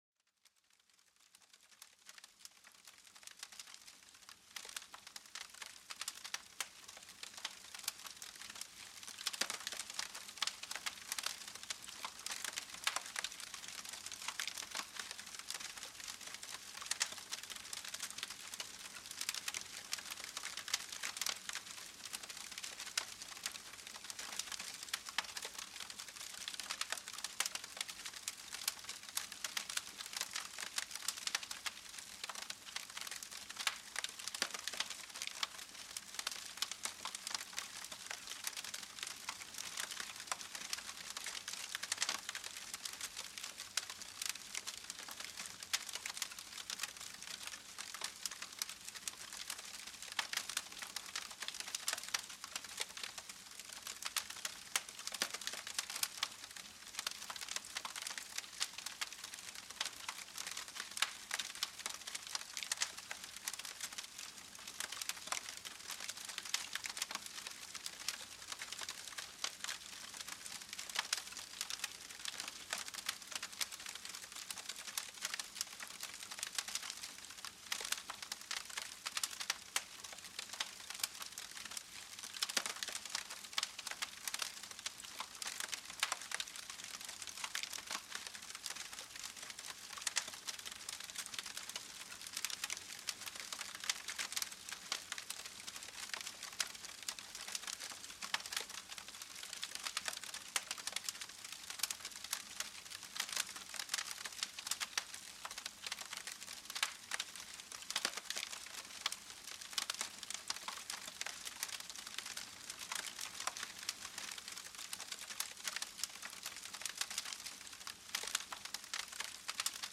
El Crepitar del Fuego: Un Calor Reconfortante para la Mente